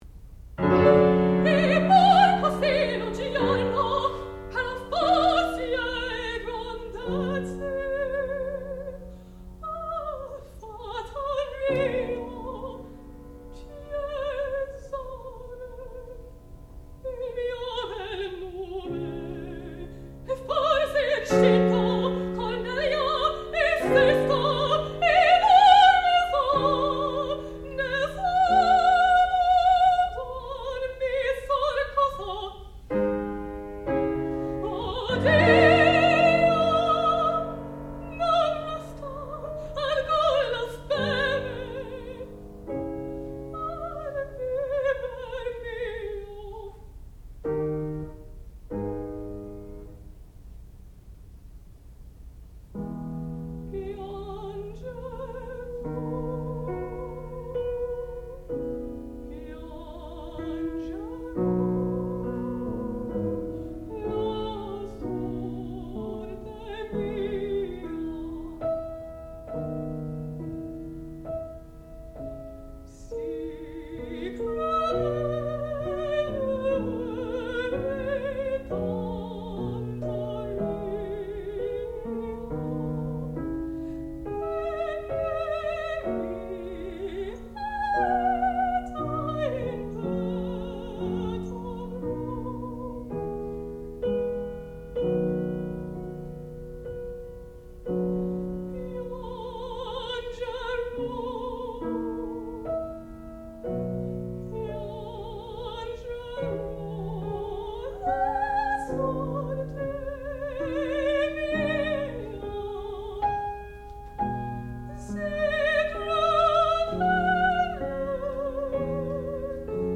sound recording-musical
classical music
Qualifying Recital
soprano